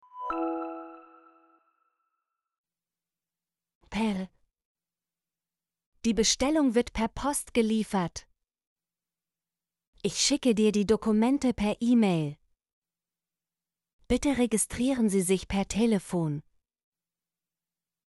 per - Example Sentences & Pronunciation, German Frequency List